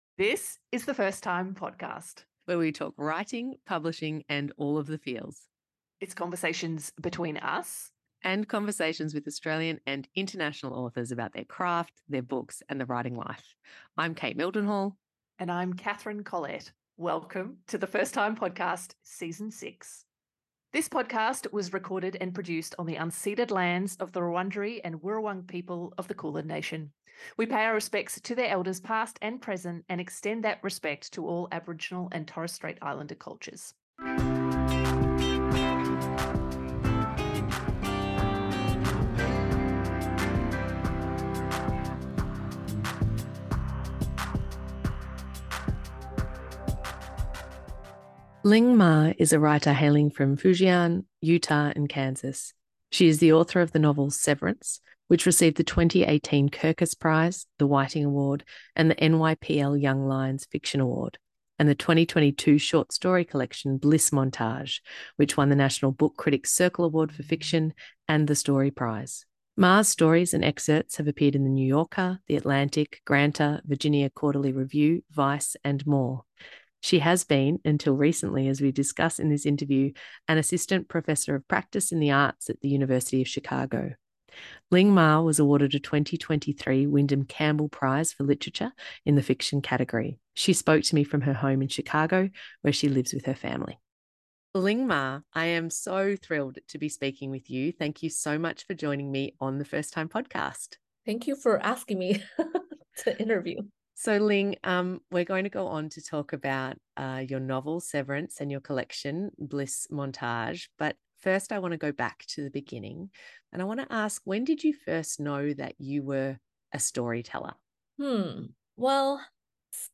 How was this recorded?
She spoke to me from her home in Chicago where she lives with her family.